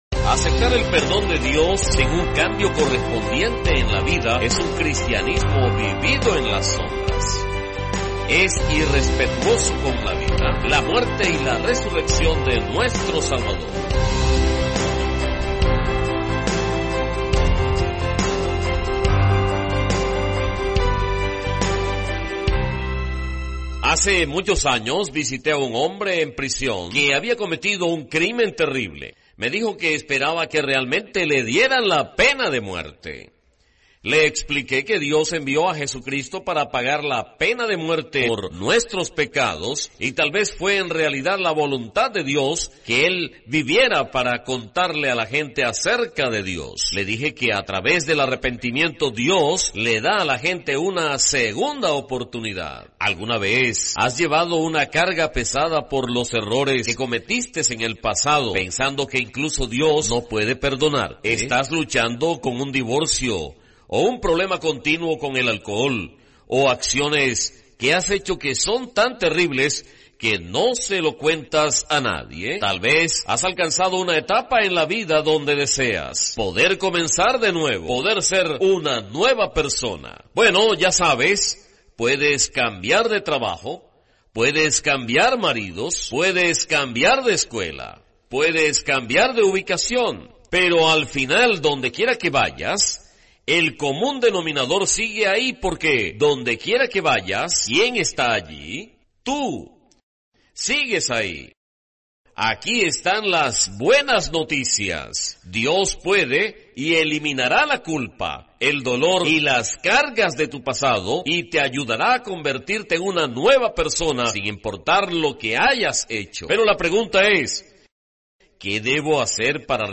Beyond Today programa de televisión ¿Es necesario el bautismo?